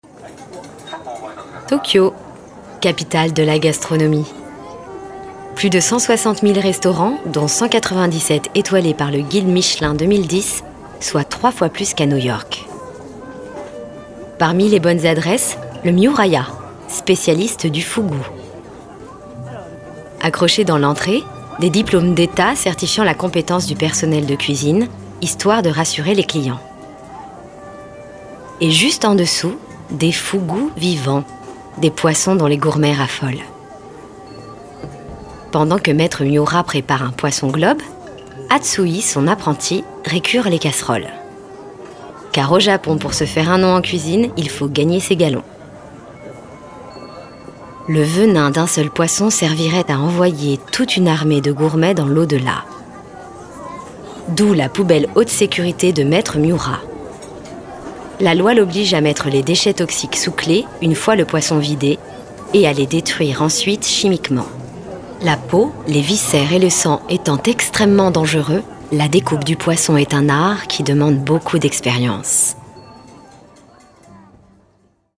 Bandes-son
Comédienne